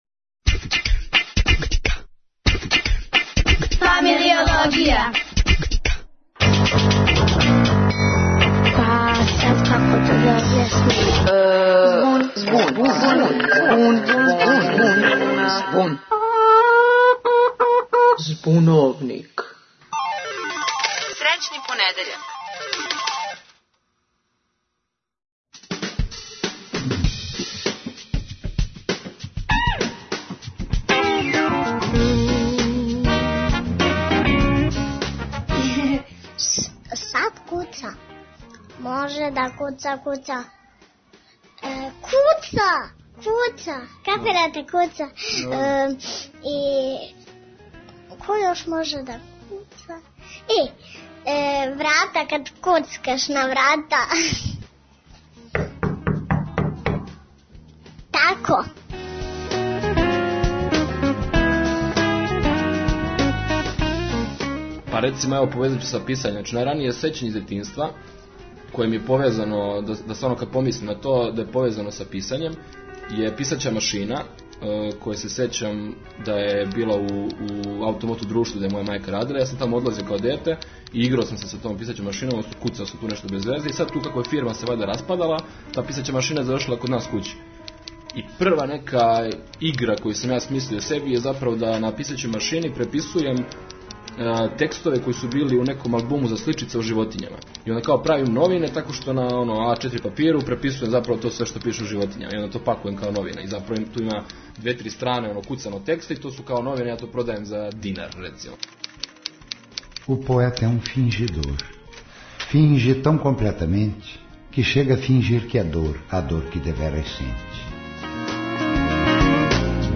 Ко куца, где куца, зашто куца и, што је најважније, шта и како куца, разјасниће нам деца, часовничари, песници и радио-аматери.